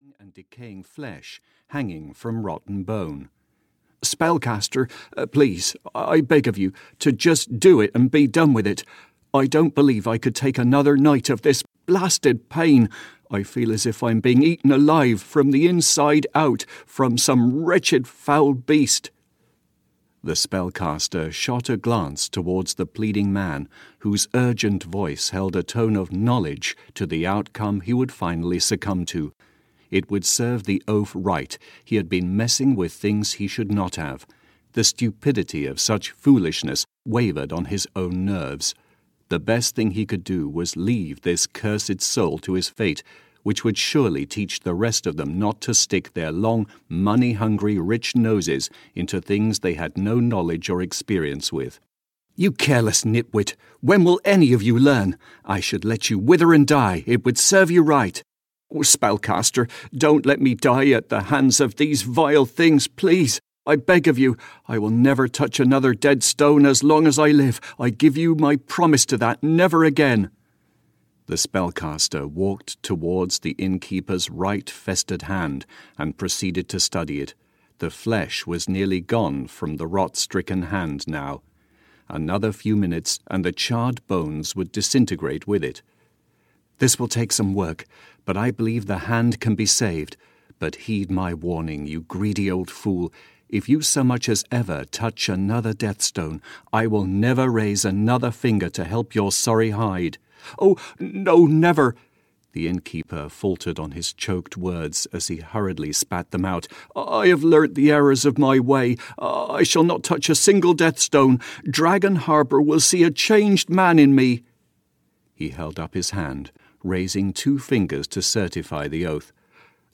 Dragon World (EN) audiokniha
Ukázka z knihy